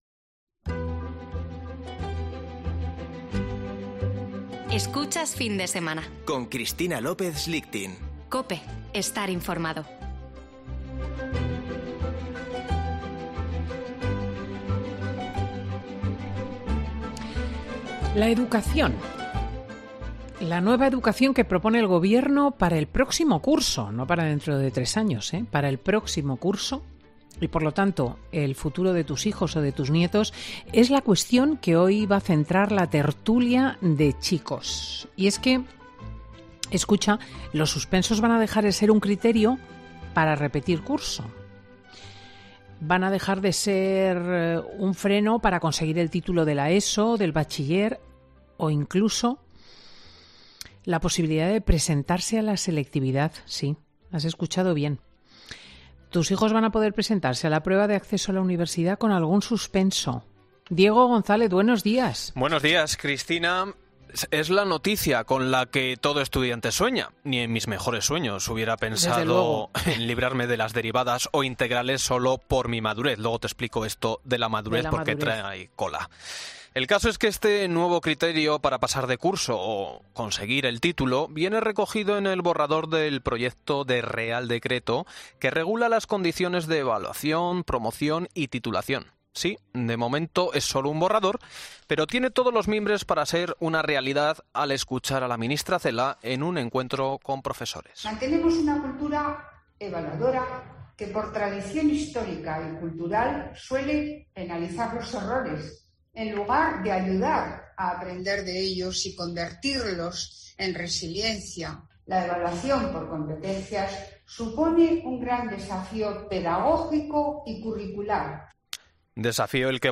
Tertulia de chicos: pasar de curso sin límite de suspensos